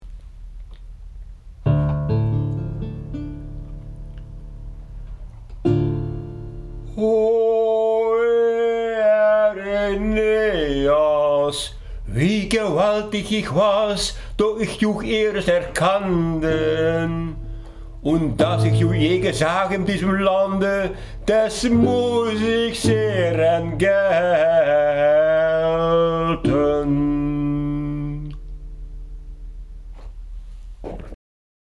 Afgezien van mijn zangkunsten die niet al te best zijn, heb ik toch grote twijfels of het zo ongeveer geklonken heeft.